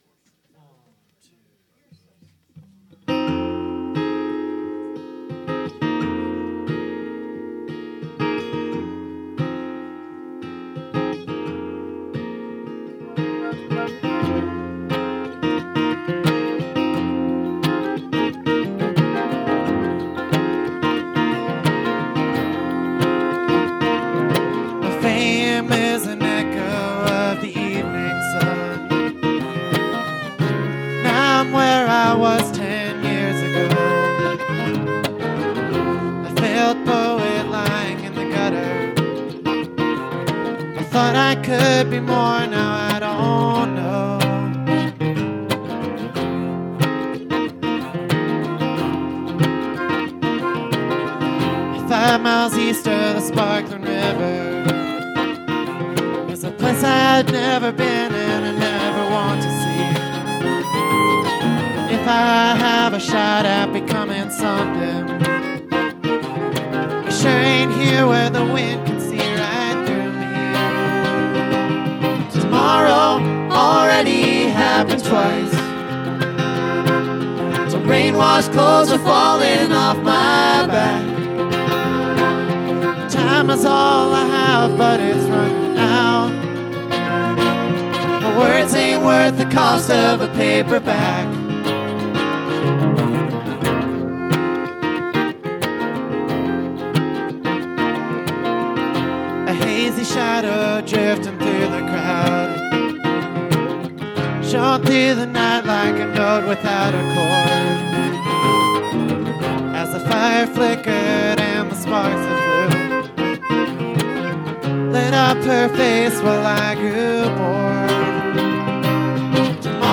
Sierra Pines Resort Sierra City, CA
Guitar, Octave Mandolin
Mandolin, Guitar
Fiddle